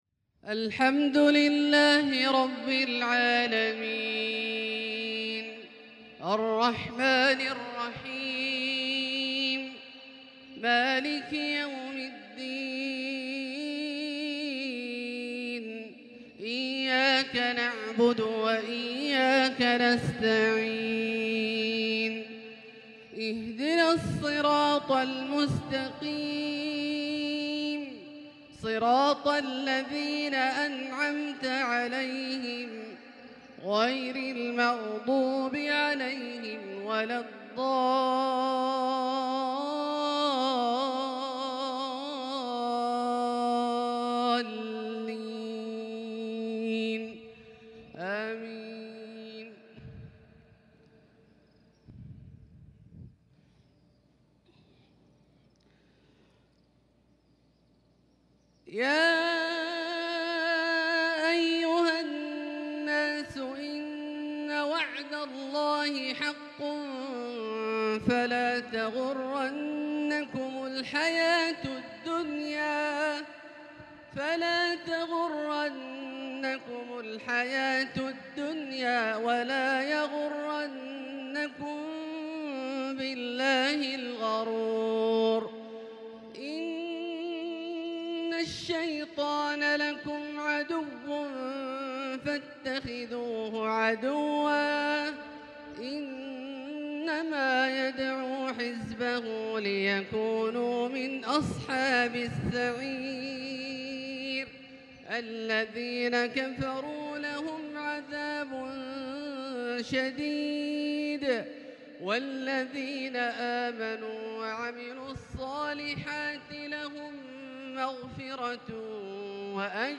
Maghrib Prayer from Surat Fatir 9-6-2021 > H 1442 > Prayers - Abdullah Al-Juhani Recitations